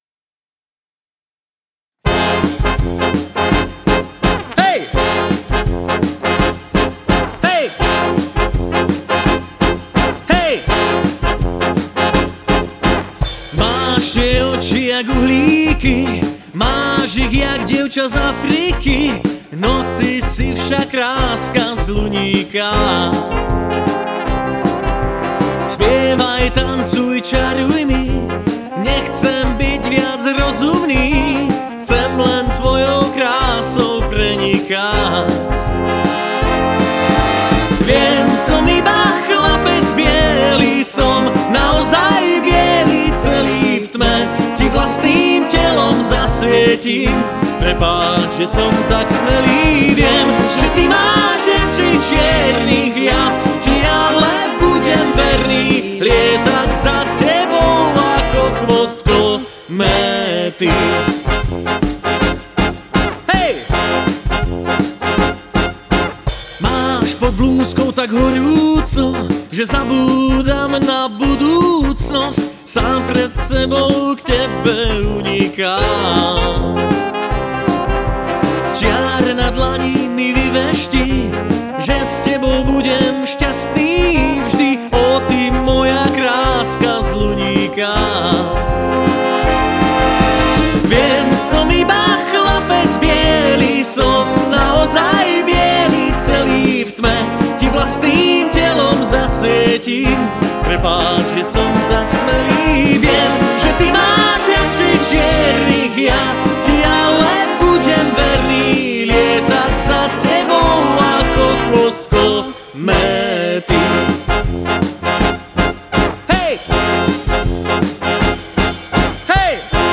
Dychová hudba Značky
Tanečné skladby